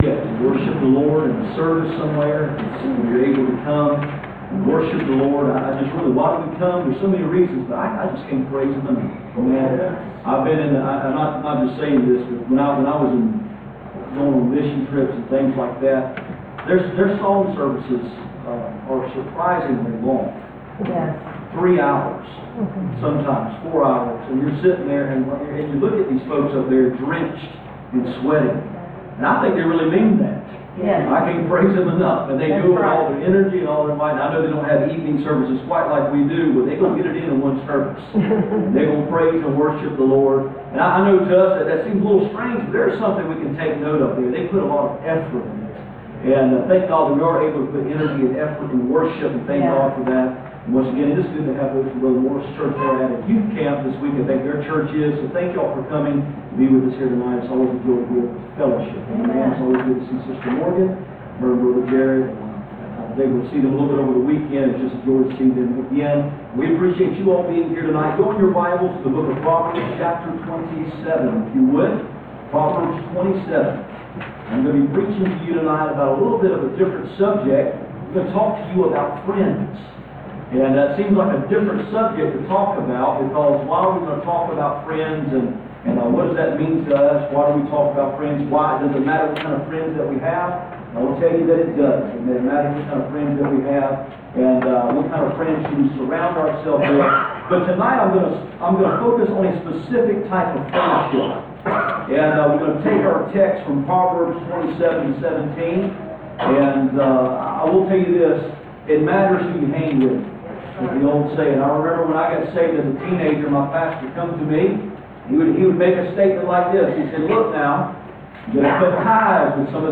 Passage: Proverbs 27:17 Service Type: Sunday Evening %todo_render% « A leper that sang a solo Examples and Warnings